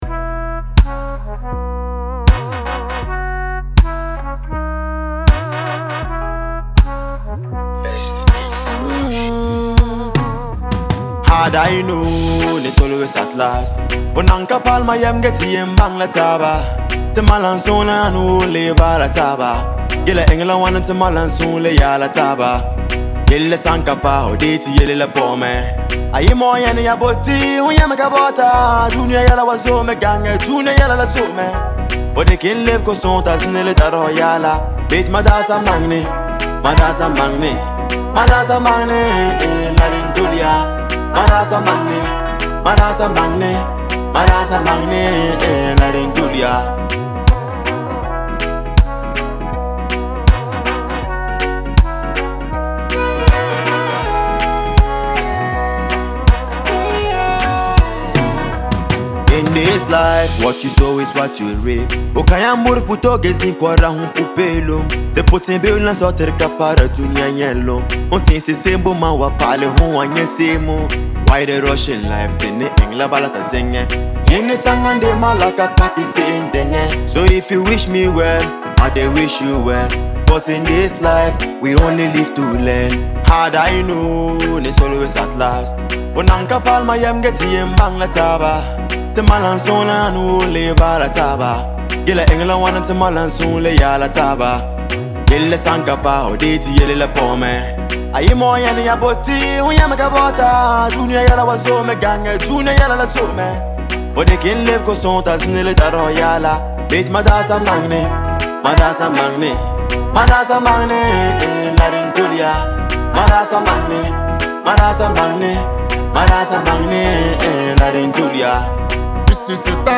reggae track